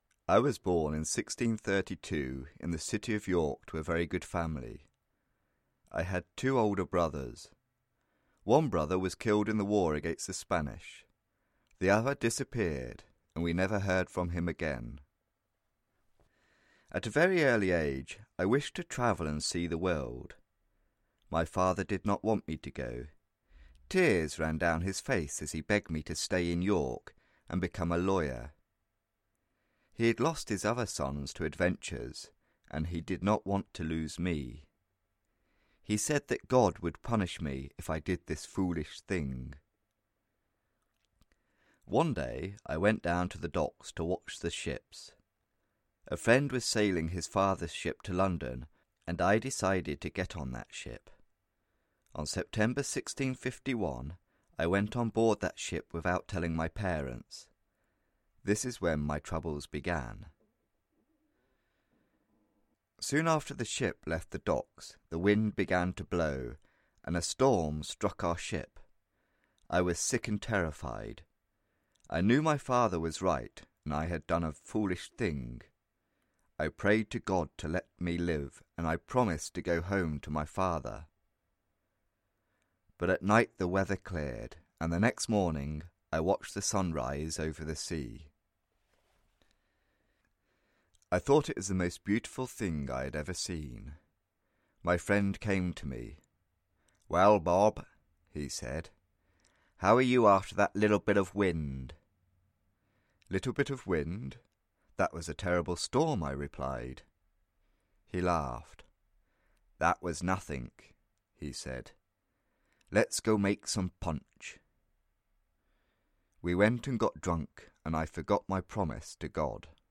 Audio kniha
Ukázka z knihy
Pro pokročilé studenty angličtiny jsme vytvořili jeho zjednodušenou verzi namluvenou anglickým hercem.